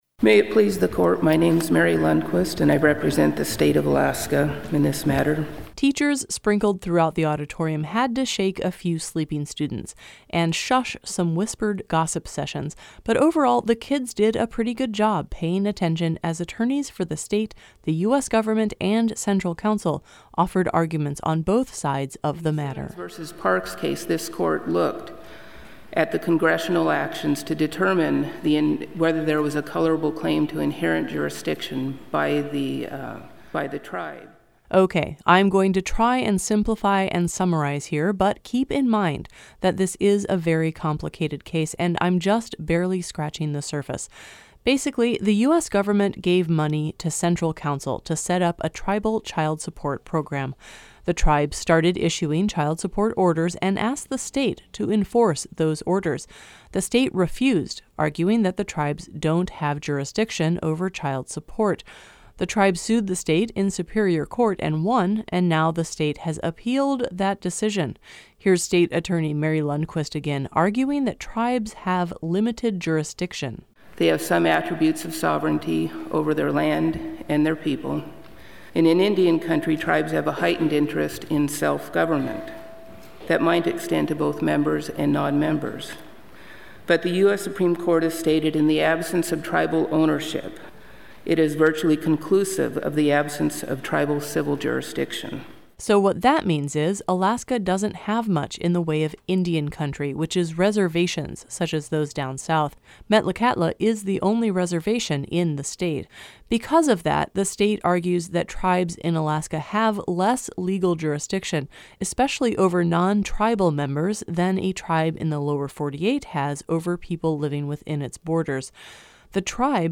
But instead of a courtroom, it’s heard at a high school.
In the audience, Kayhi’s approximately 800-seat auditorium was packed, mostly with students, although some members of the public attended the event, as well.
Students ask questions of the attorneys following oral arguments in the Supreme Court Live event.